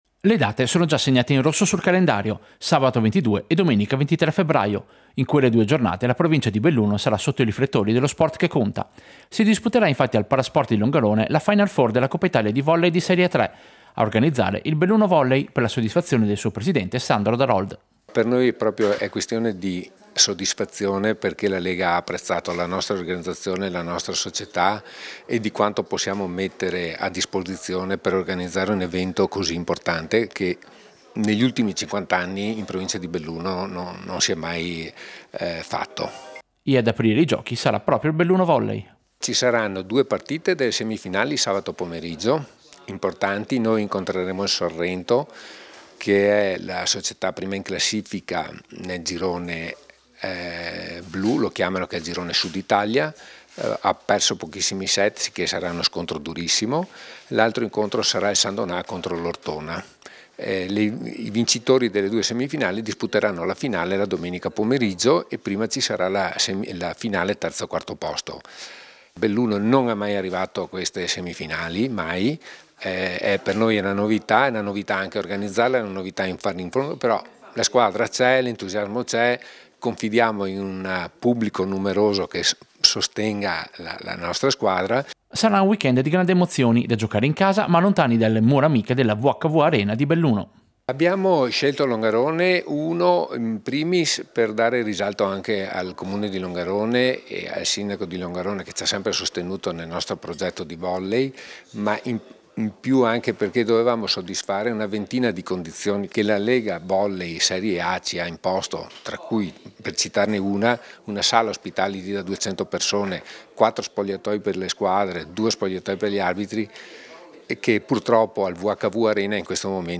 Servizio-Final-Four-Volley-Longarone.mp3